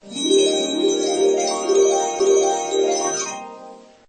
Fairytale Transition
fairytale-transition.mp3